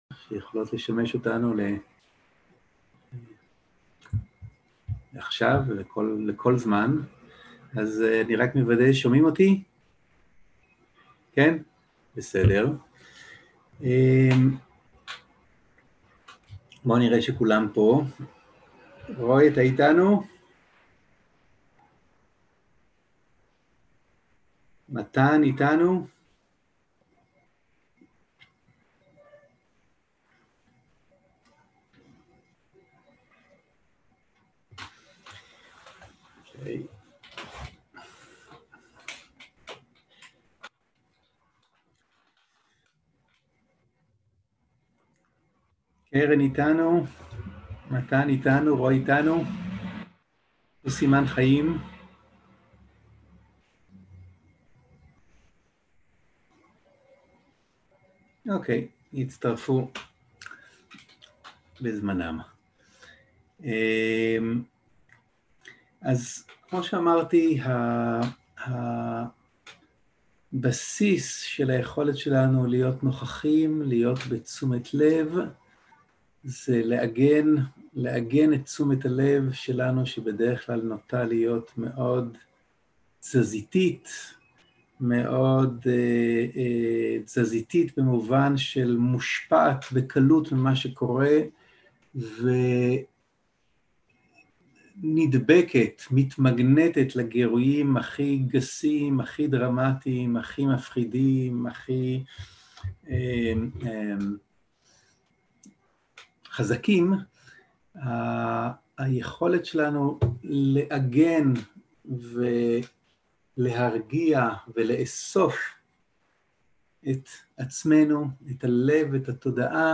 הנחיות למדיטציה - עיגון וביסוס תשומת לב בתחושות הגוף 0:00 0:00 סוג ההקלטה
Guided meditation